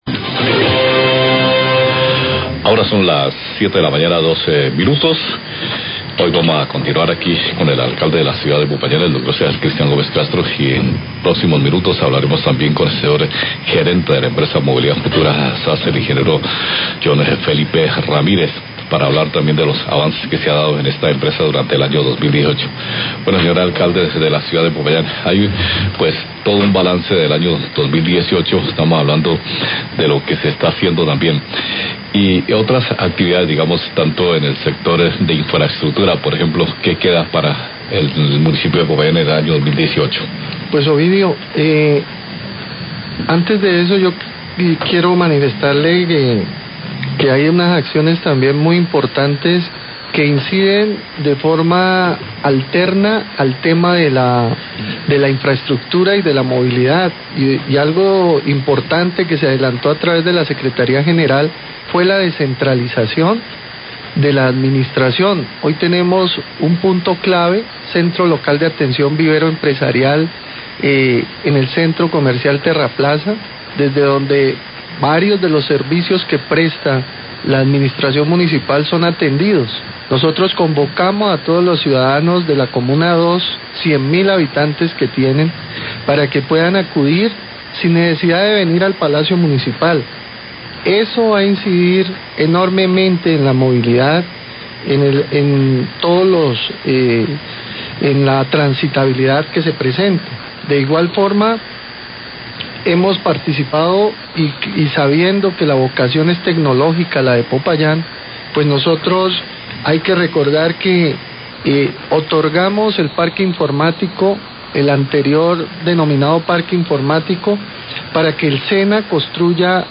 Radio
Declaraciones del Alcalde de Popayán, Cesar Cristian Gómez.